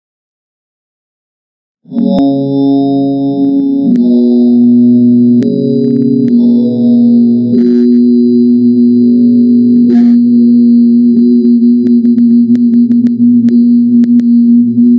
SpookyBassSFX
Bass tone nDeep bass nBass resonance nLow bass nBass vibration